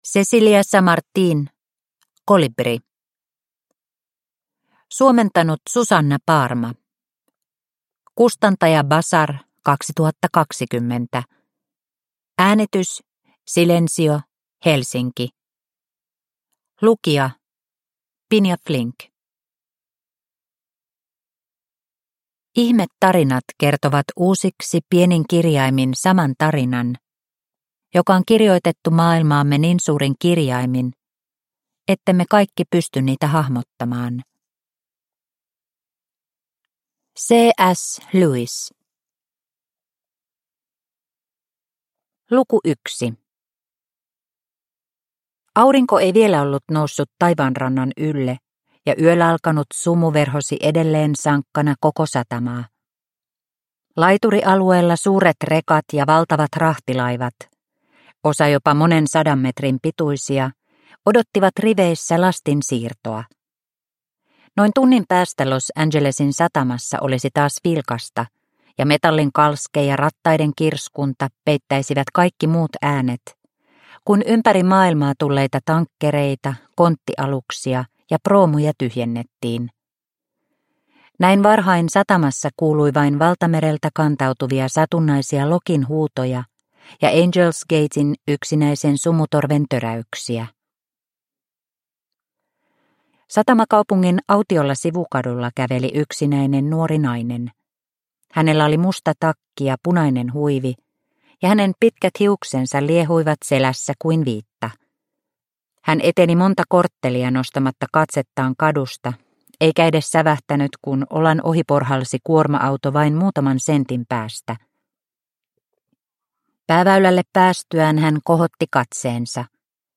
Kolibri – Ljudbok – Laddas ner